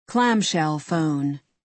Файл:Clamshell phone.wav — Encyclopedia Electronica
Clamshell_phone.wav